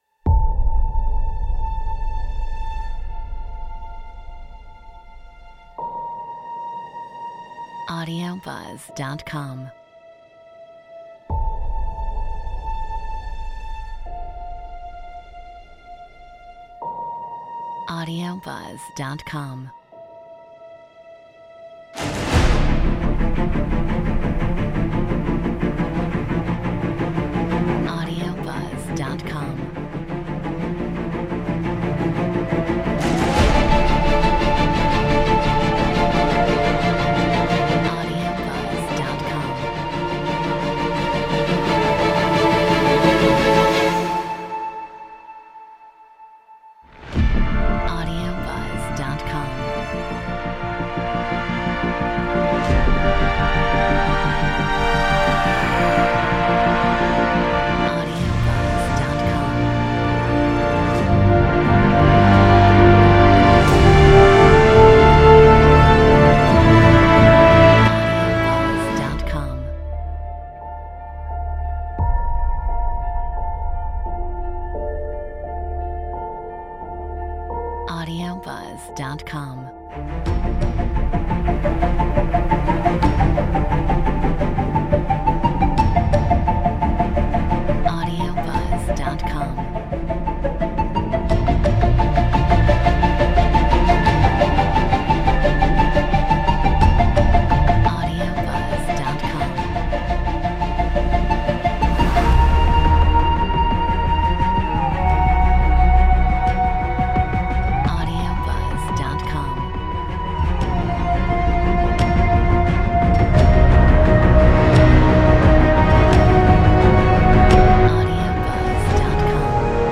Metronome 87